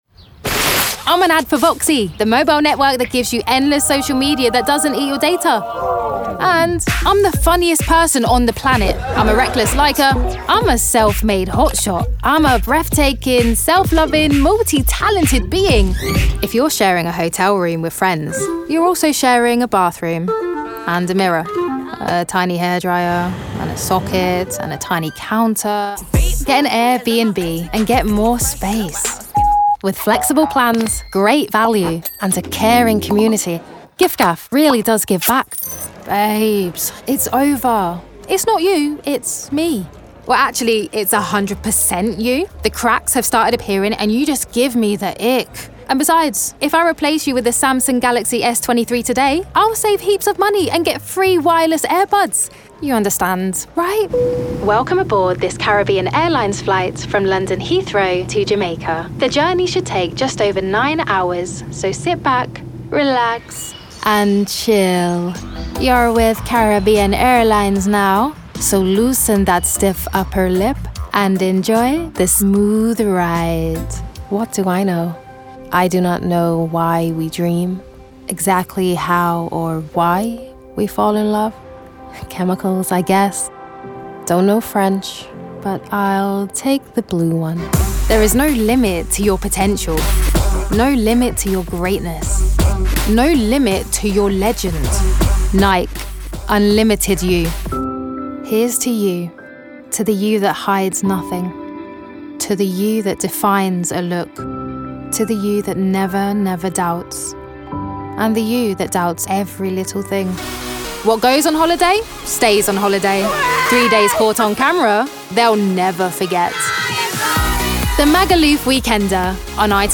Commercial
teens-20s - authentic, fresh, smart
Standard English/RP, London/Cockney, Caribbean, Northern (English), Eastern Europe - Bulgarian/Slovenian/Russian
Actors/Actresses, Attitude, Modern/Youthful/Contemporary, Natural/Fresh, Quirky/Interesting/Unique, Character/Animation, Upbeat/Energy